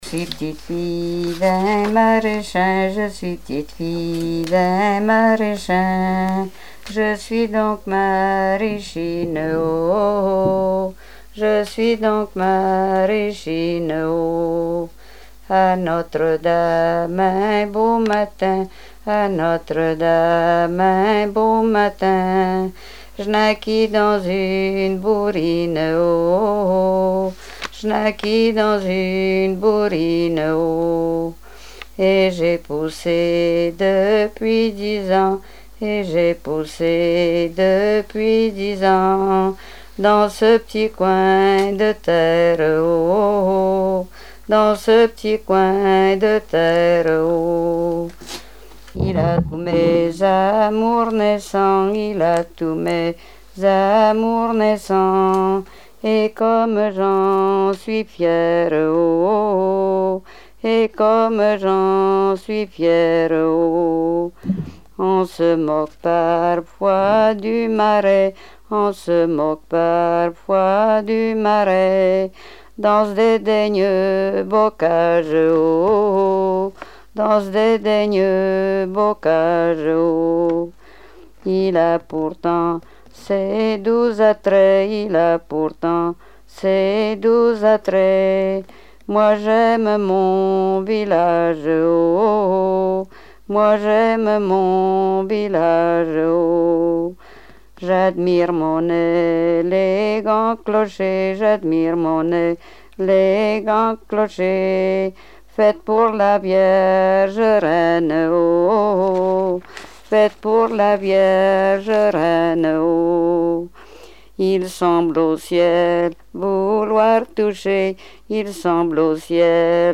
Mémoires et Patrimoines vivants - RaddO est une base de données d'archives iconographiques et sonores.
Répertoire de chansons traditionnelles et populaires
Pièce musicale inédite